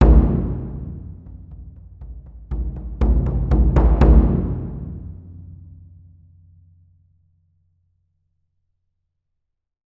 その響きを再現するために、TAIKO THUNDERはスタジオではなくホールでサンプリング収録を行いました。
• StageF：ステージ前方（客席側）のマイクポジションのサウンド（ステレオ）です。